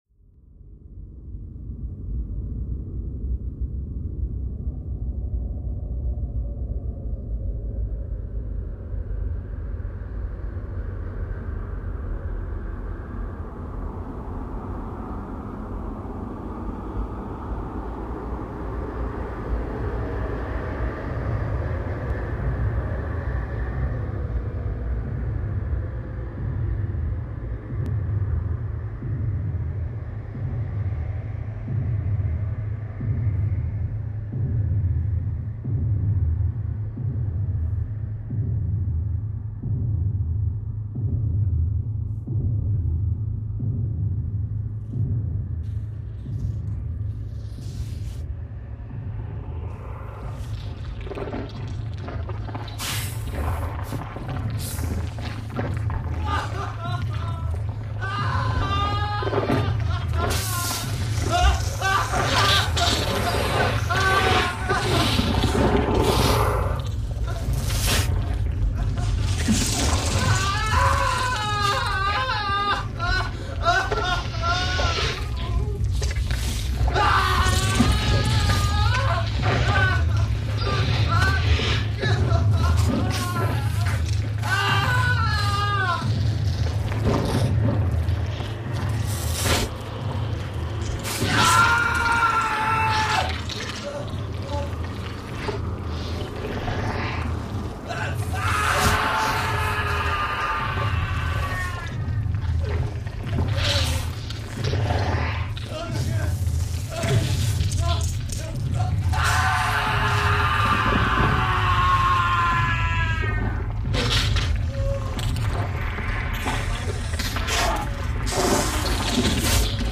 Звуки дьявола
Звуки дьявола в преисподней